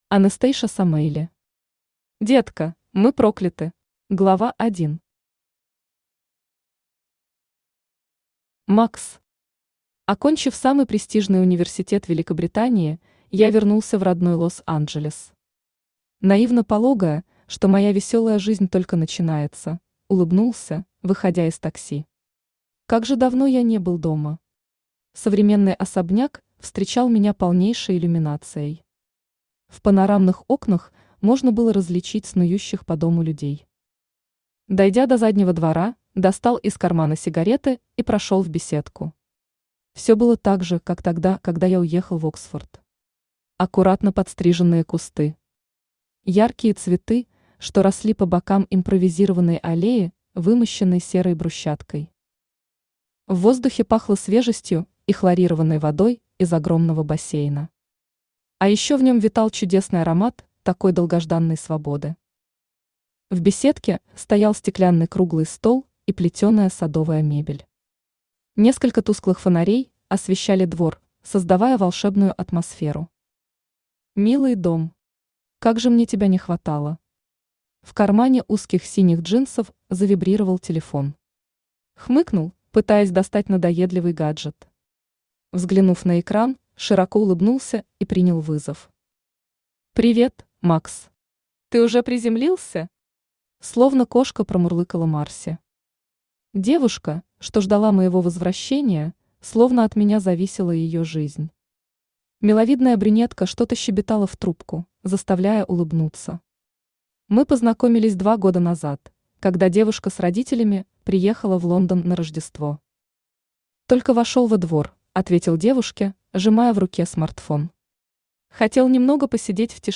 Аудиокнига Детка, мы прокляты | Библиотека аудиокниг